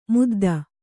♪ mudda